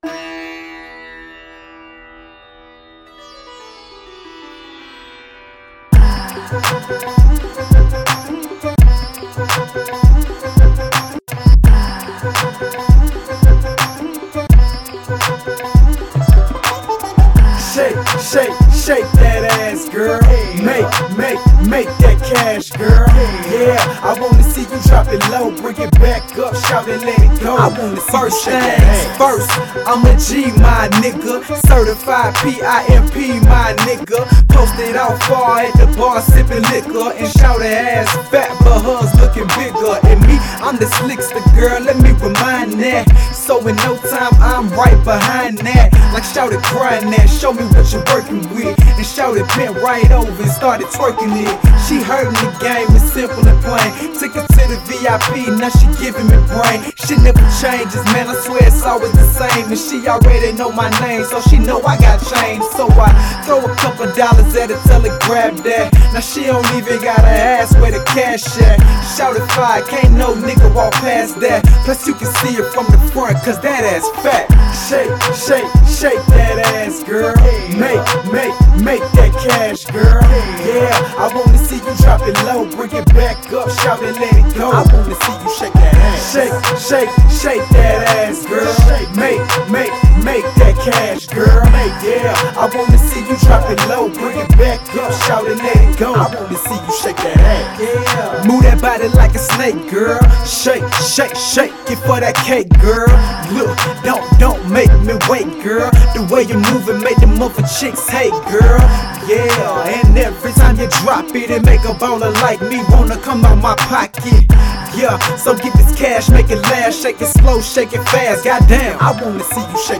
Genre: Southern Rap.